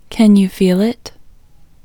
LOCATE Short OUT English Female 12